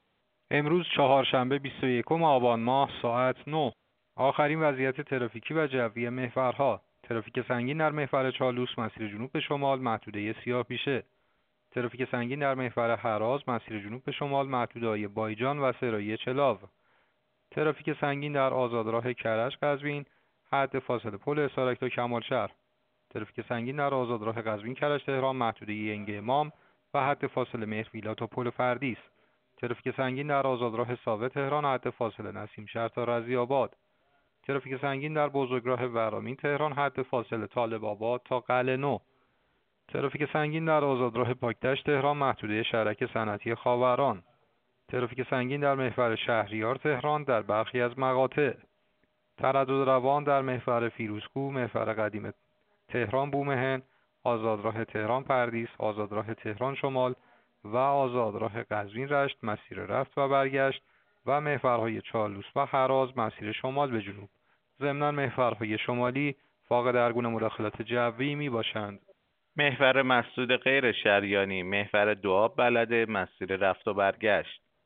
گزارش رادیو اینترنتی از آخرین وضعیت ترافیکی جاده‌ها ساعت ۹ بیست و یکم آبان؛